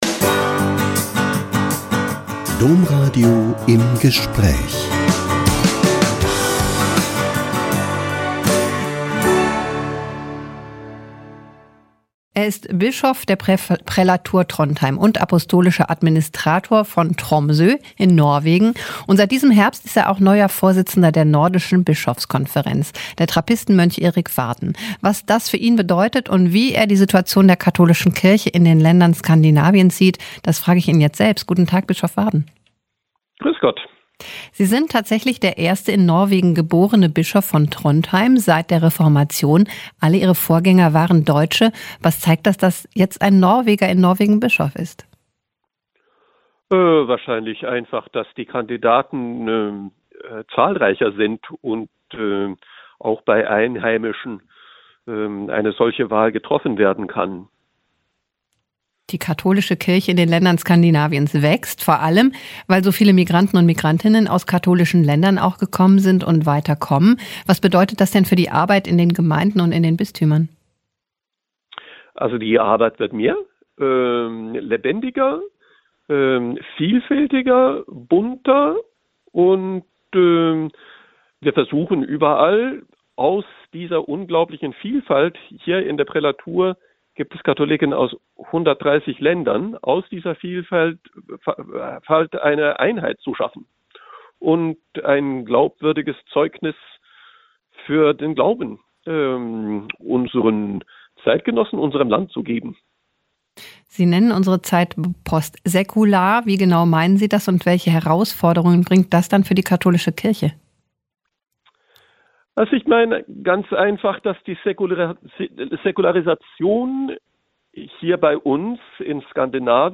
Bischof von Trondheim sieht in Evangelisierung wichtigste Aufgabe - Ein Interview mit Erik Varden (Bischof der Prälatur Trondheim, Apostolischer Administrator von Tromsö in Norwegen, Vorsitzender der Nordischen Bischofskonferenz)